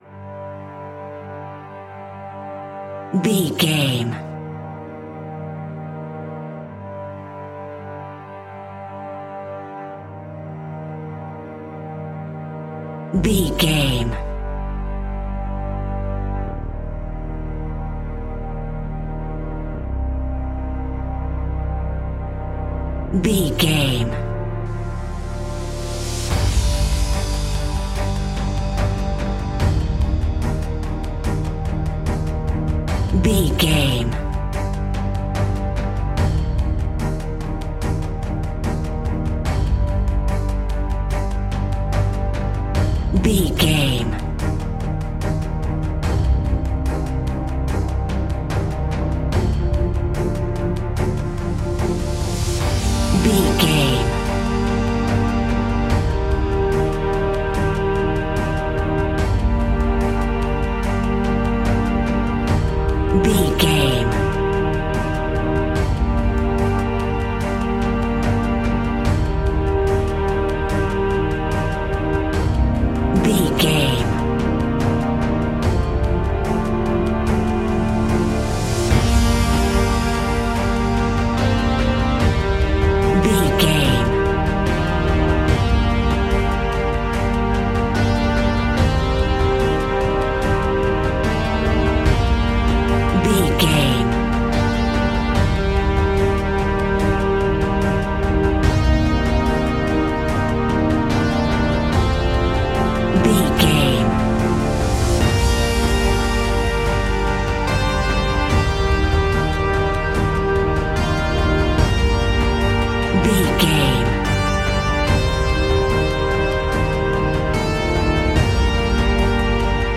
Epic / Action
Fast paced
In-crescendo
Aeolian/Minor
dramatic
strings
brass
percussion
synthesiser